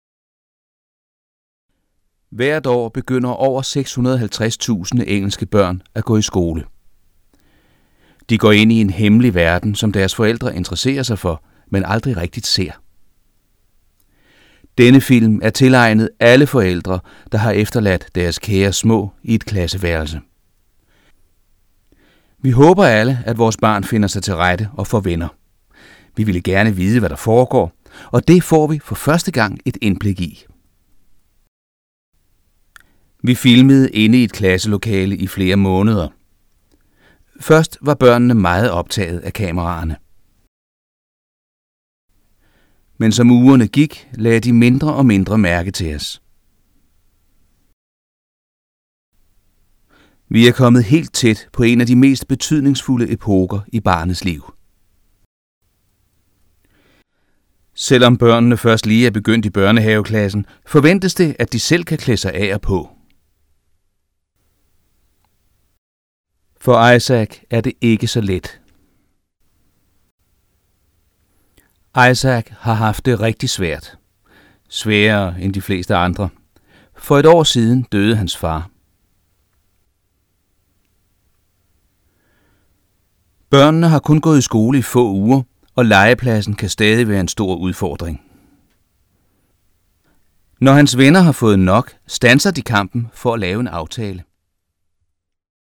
Experienced voiceover talent.
Sprechprobe: Sonstiges (Muttersprache):
Actor, presenter, voiceover.